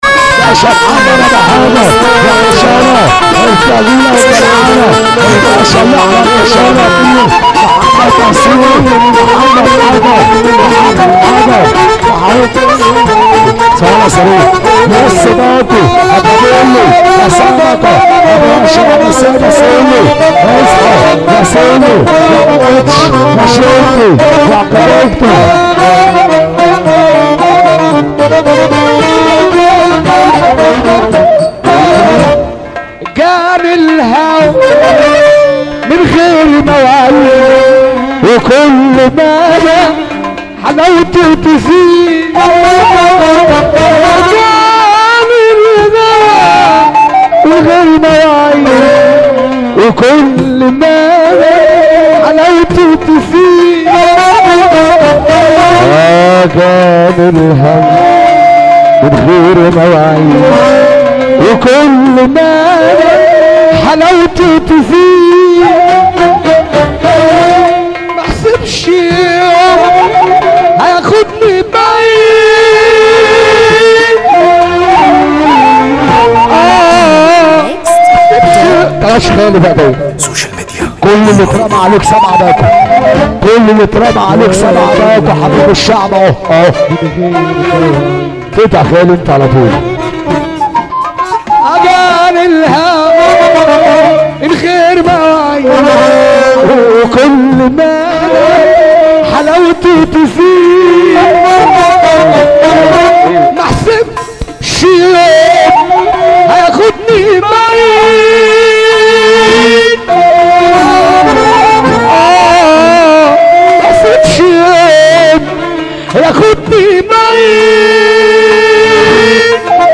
موال